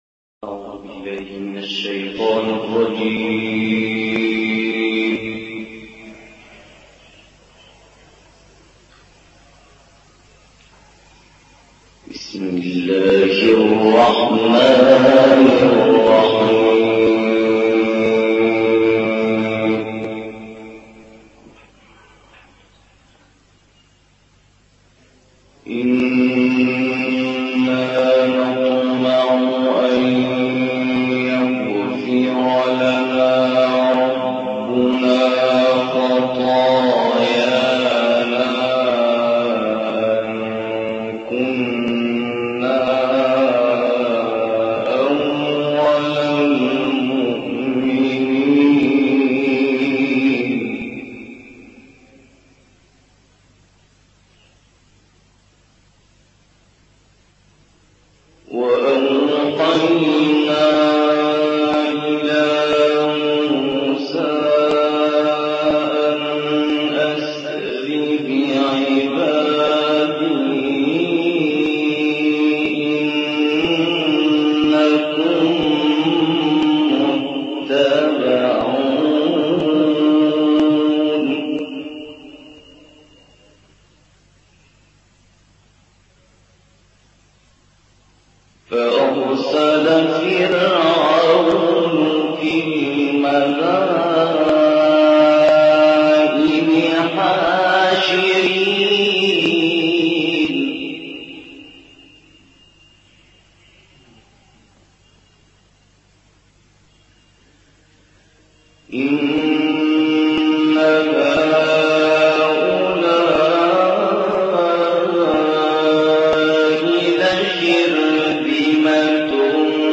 تلاوت مجلسی